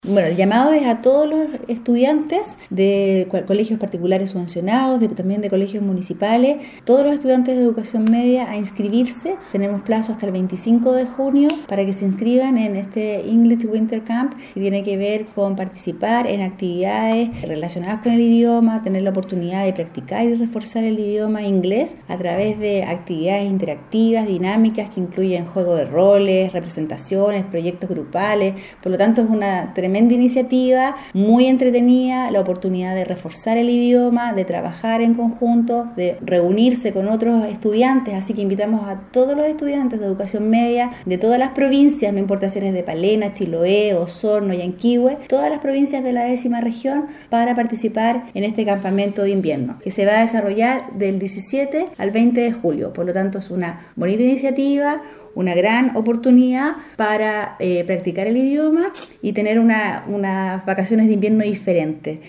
Al respecto, la Seremi de Educación Claudia Trillo, hizo un llamado a los estudiantes, invitando y a aprovechar la oportunidad de reforzar las habilidades con el idioma inglés durante este invierno.